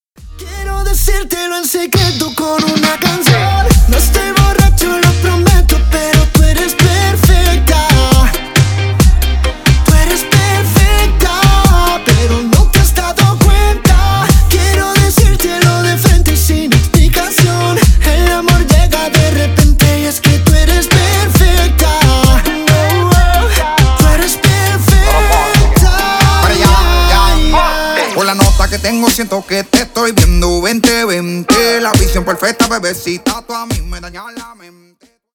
Танцевальные
латинские